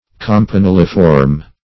Definition of campaniliform.
Search Result for " campaniliform" : The Collaborative International Dictionary of English v.0.48: Campaniliform \Cam`pa*nil"i*form\, a. [See Campaniform .] Bell-shaped; campanulate; campaniform.